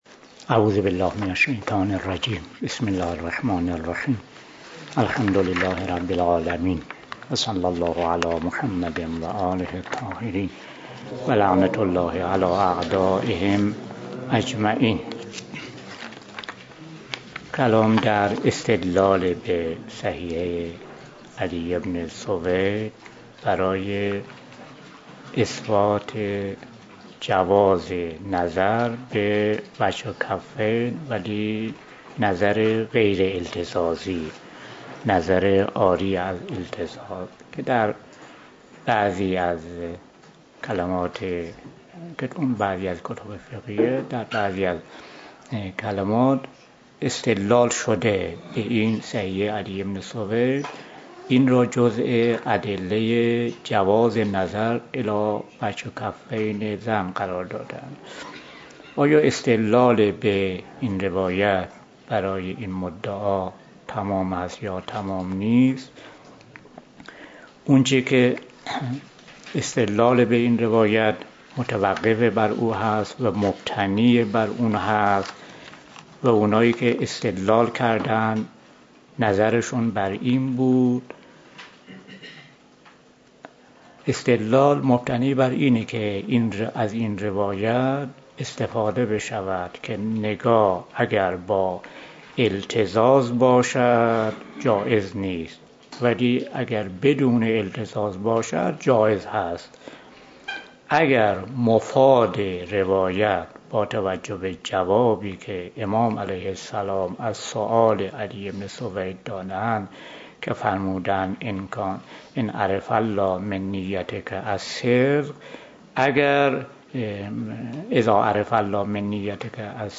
متن درس فقه عبادات (خلل قبله)